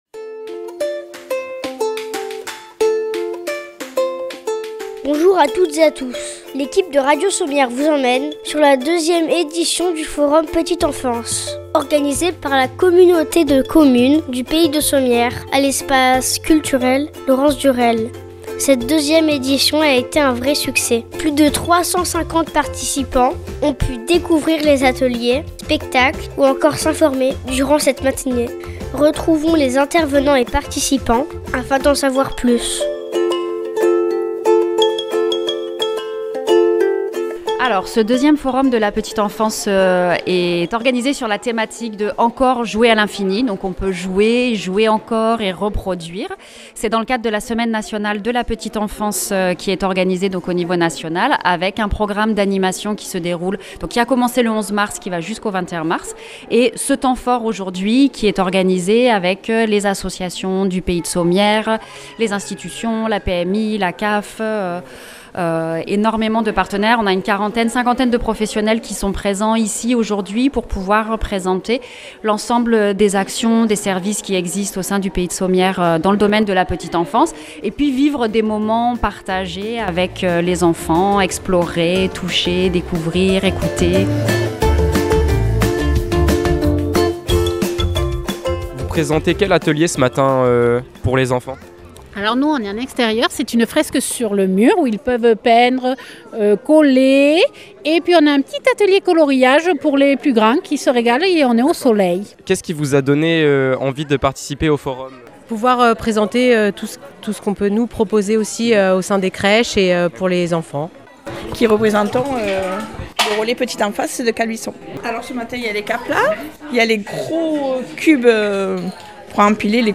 Reportage sur le Forum petite enfance de Sommières